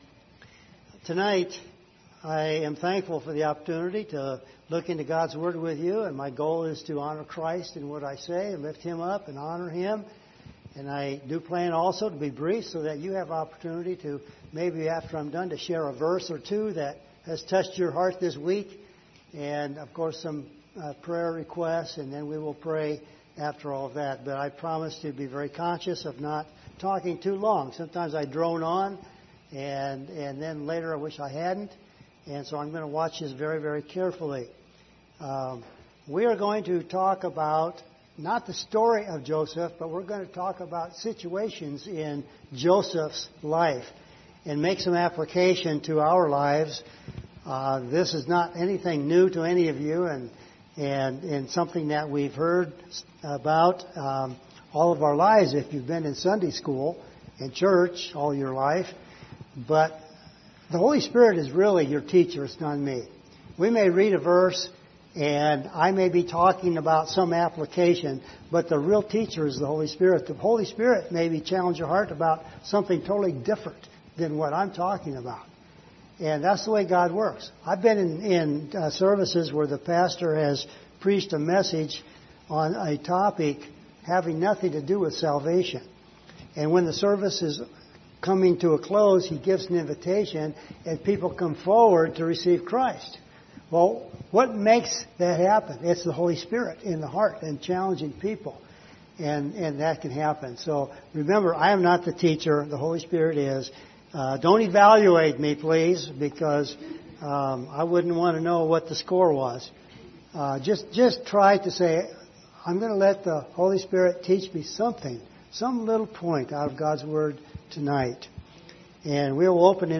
Isaiah 64 Service Type: Wednesday Evening View this video on Facebook « How are we Looking at this World?